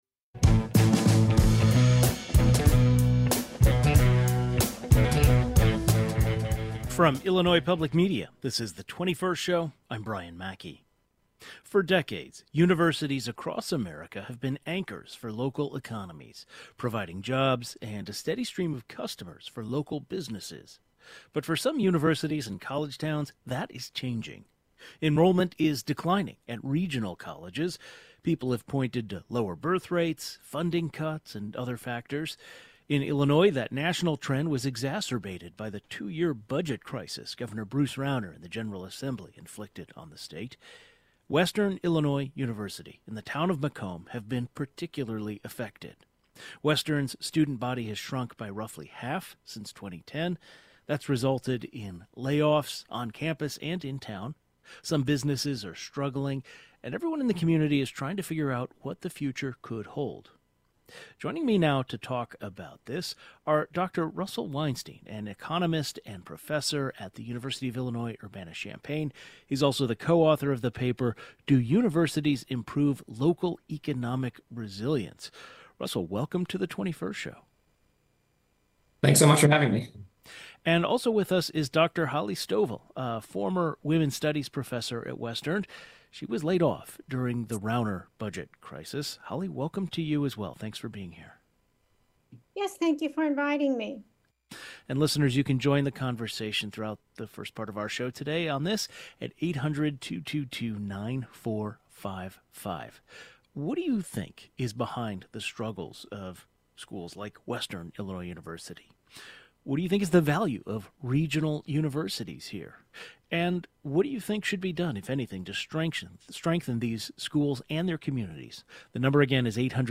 Some businesses are struggling and everyone in the community is trying to figure out what the future could hold. A former professor, who was laid off from the university during the budget crisis and an economic expert join the conversation.